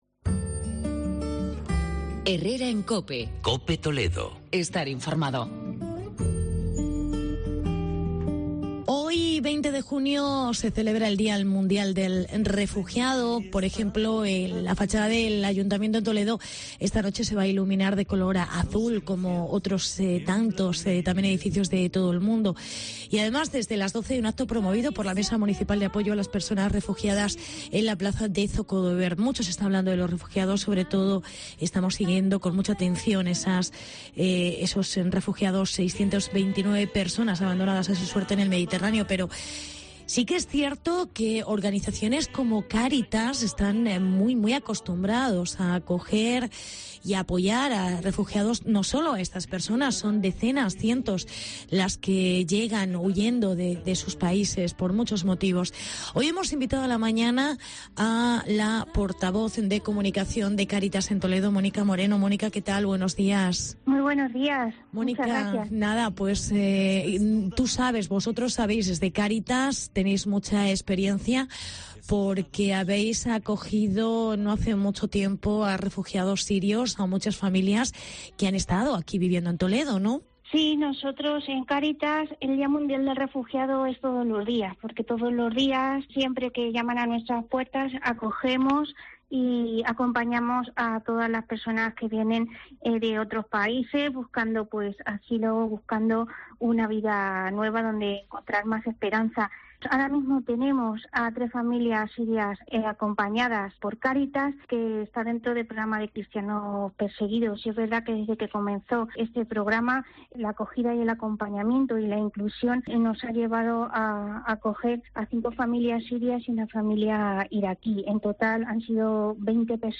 Día Mundial del Refugiado. Entrevista